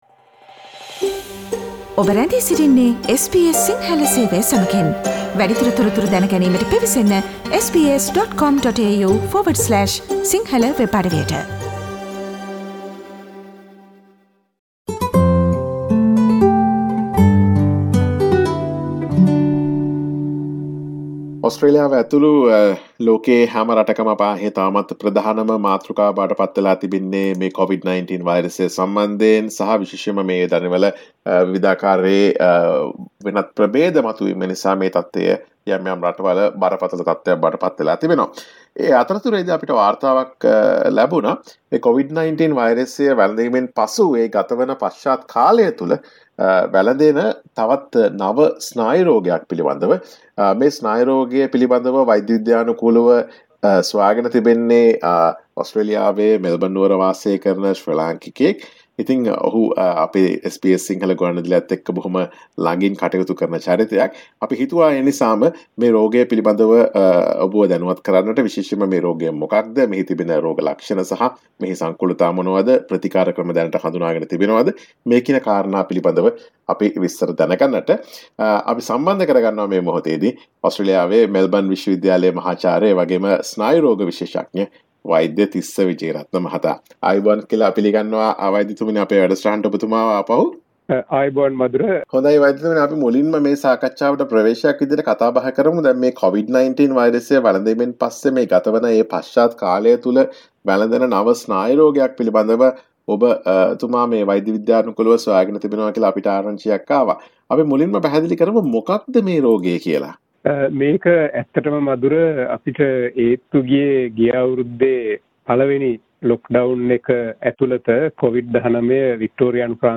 එම සොයාගැනීම පිළිබඳ SBS සිංහල ගුවන් විදුලිය ගෙන එන සාකච්ඡාවට සවන් දෙන්න. Share